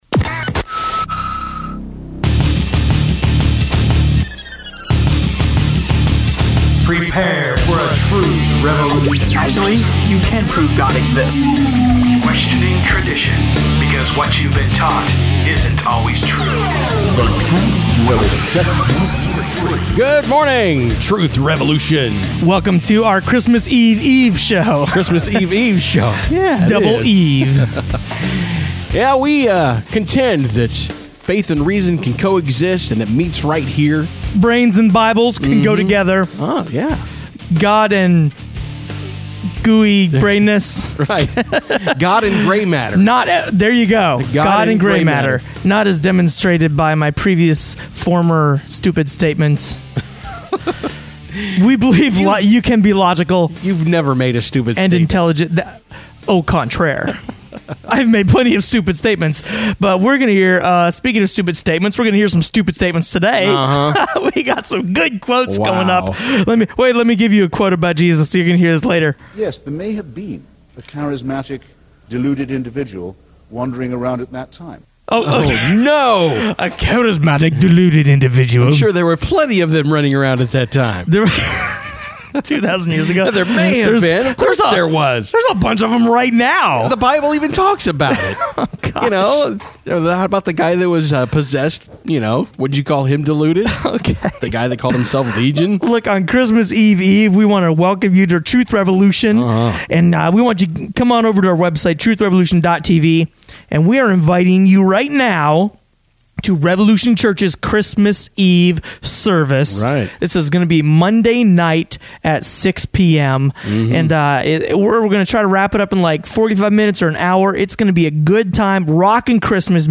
Was There An Historical Jesus? – Truth Revolution Radio Show
Refuting Christopher Hitchens Focus on the Census and Governor Quirinius: Luke 2:1-2 Discussing Historians that Prove the Claims in the Bible Matthew 27:45 proven in Roman Historical Documents This show originally aired December 23rd, 2012